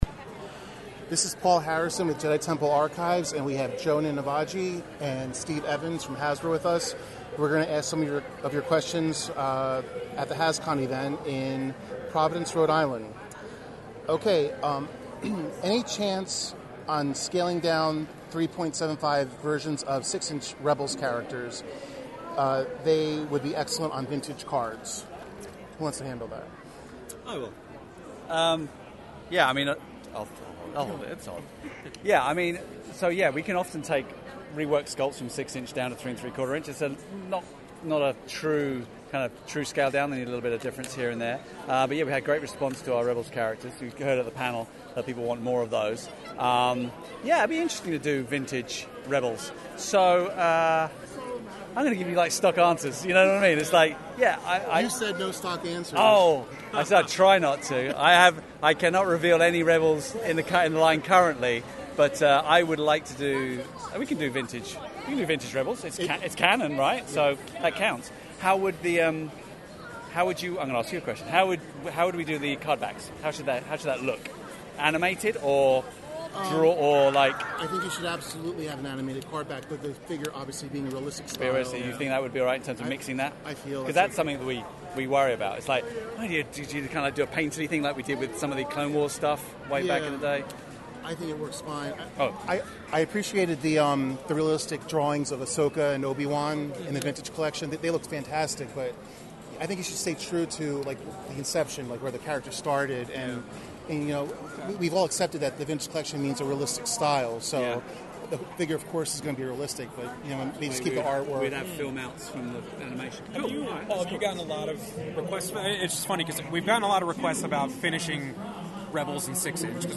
HASCON17: Jedi Temple Archives Interviews Hasbro
Listen to our full interview with Hasbro at HASCON 2017 using the player below.
HASCON-2017-Hasbro-Interview-JTA.mp3